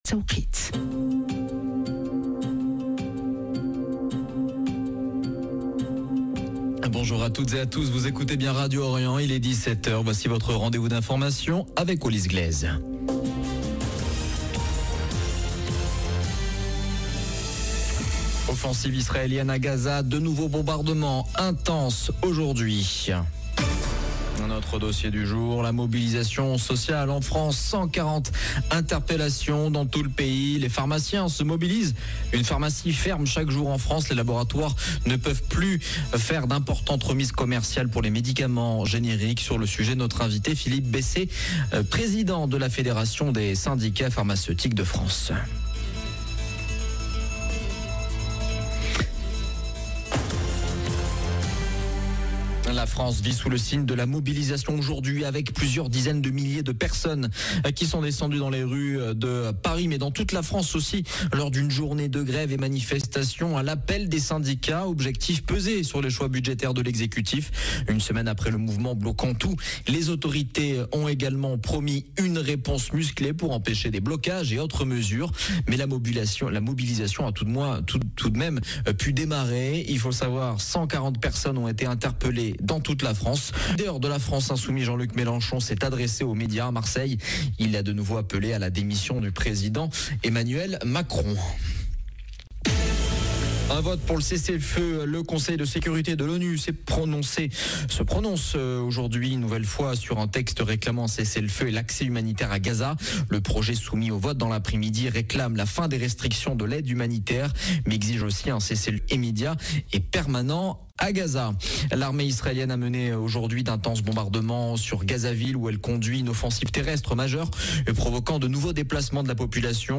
Journal de 17H du 18 septembre 2025